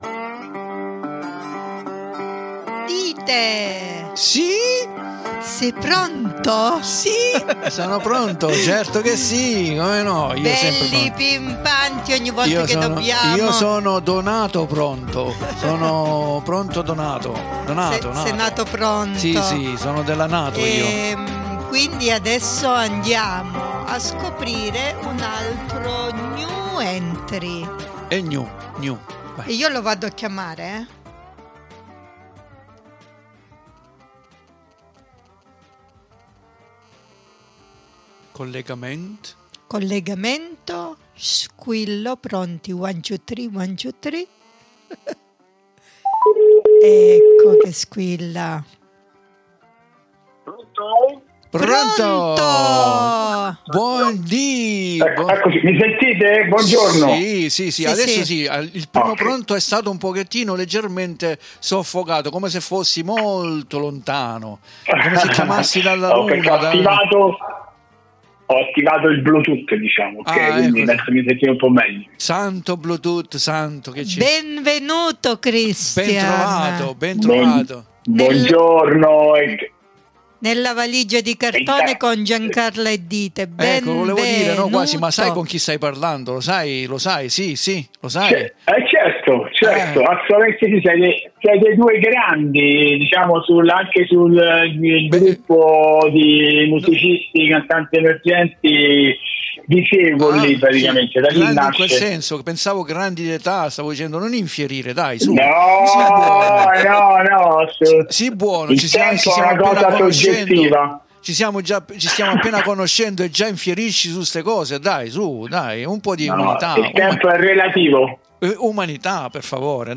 PER NON SPIFFERARE TUTTO VI LASCIO ALLA SUA INTERVISTA , CONDIVISA QUI IN DESCRIZIONE E VI ALLEGO ANCHE UN LINK YOUTUBE , DOVE POEE TROVARE ALTRI SUOI LAVORI.